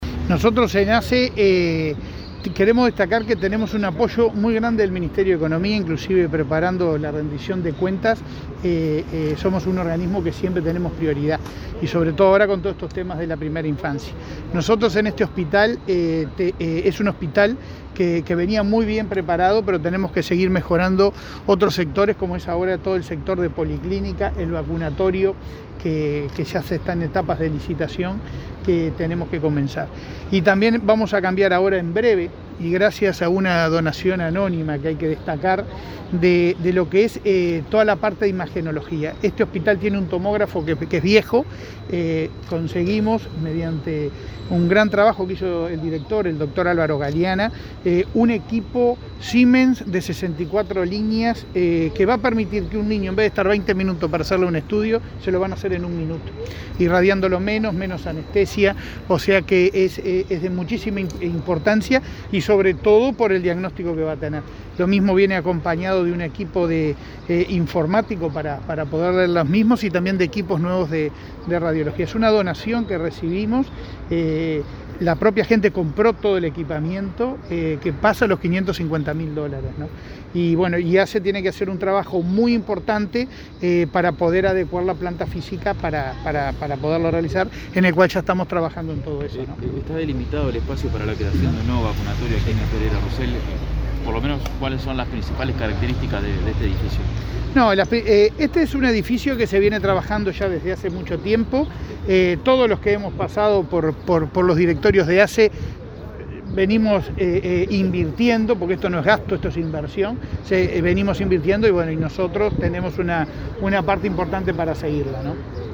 El presidente de ASSE, Leonardo Cipriani, brindó declaraciones a la prensa, este viernes 25, en el hospital Pereira Rossell.